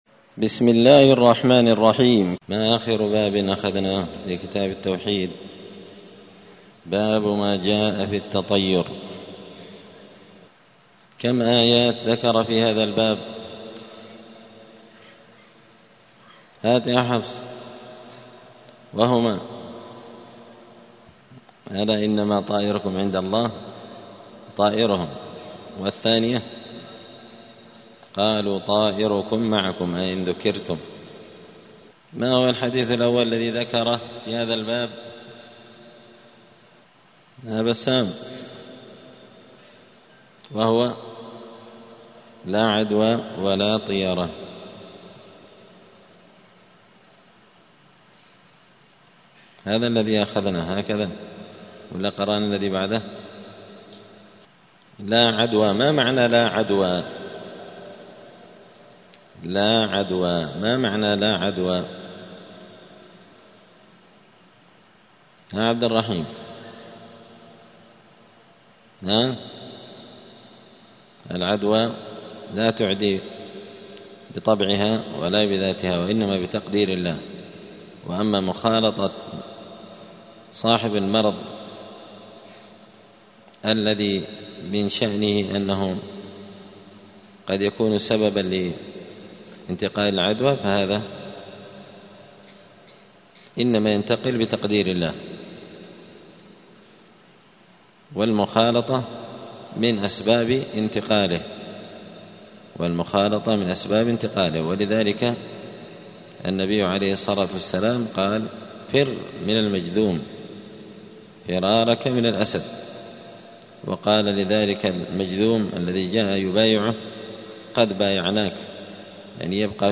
دار الحديث السلفية بمسجد الفرقان قشن المهرة اليمن
*الدرس السابع والسبعون (77) {باب ما جاء في الطيرة}*